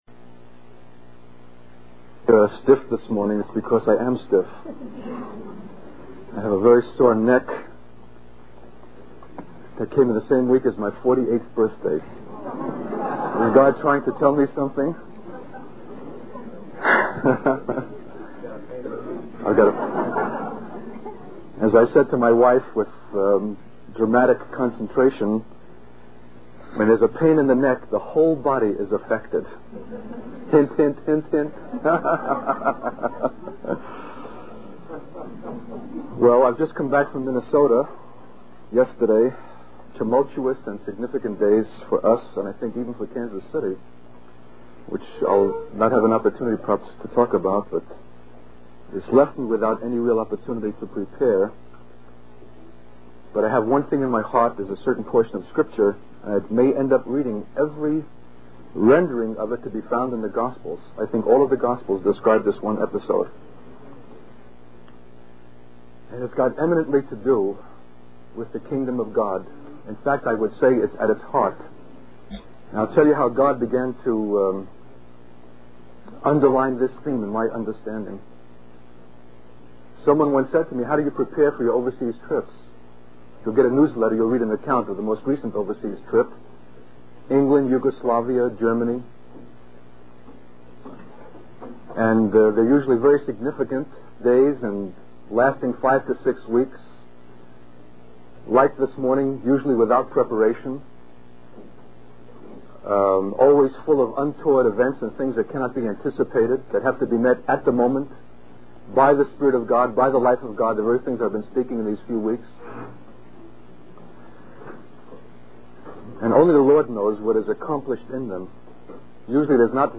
In this sermon, the speaker describes a powerful encounter where he witnessed a group of Jews challenging him and others with clever arguments. He reflects on the importance of having answers rooted in the Scriptures and being led by the life of God.